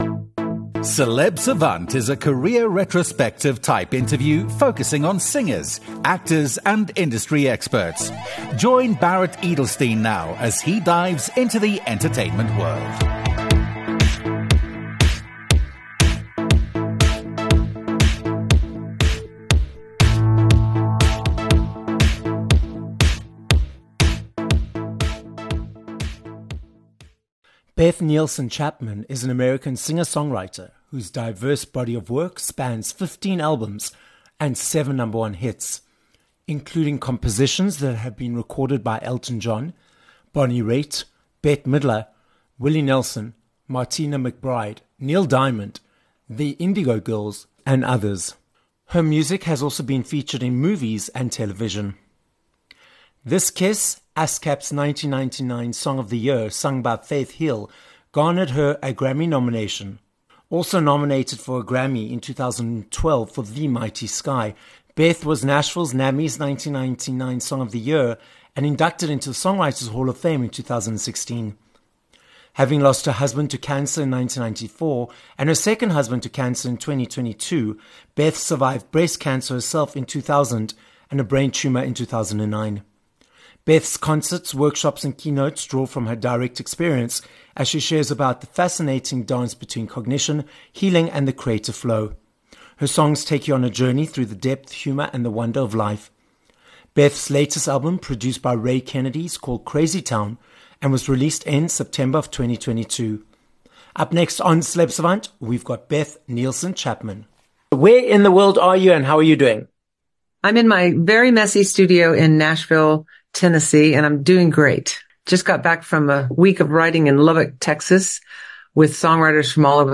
Beth Nielsen Chapman - a Grammy nominated American singer and songwriter, who has been inducted into the Songwriters Hall of Fame - joins us on this episode of Celeb Savant. Beth dives into a multi-decade and multi award-winning career that includes 15 albums, seven number ones, her journey with cancer, and how Archbishop Desmond Tutu motivated her to move forward on a project focusing on different cultures.